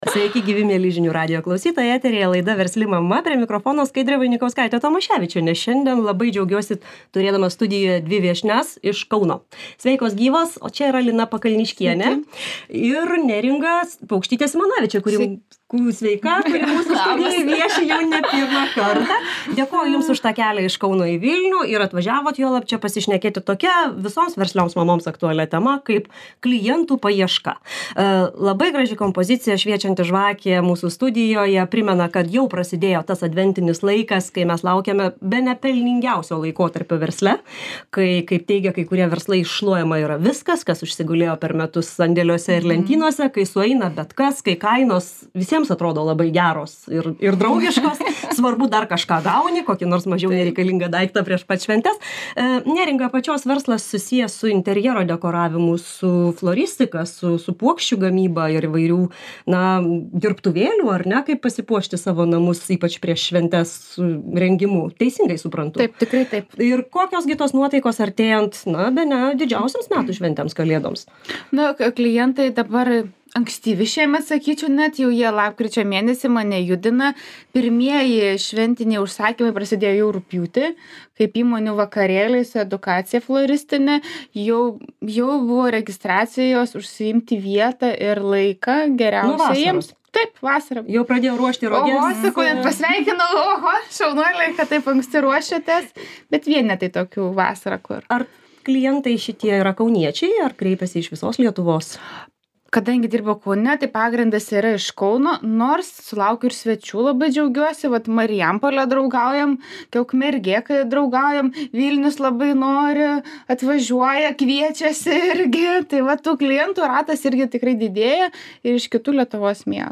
Savo patirtimi, kaip prisivilioti naujų ir išlaikyti senuosius pagrindinius verslo darbdavius – pirkėjus, dalinasi dvi kaunietės mamos